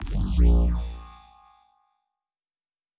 Sci-Fi Sounds / Electric
Shield Device 6 Start.wav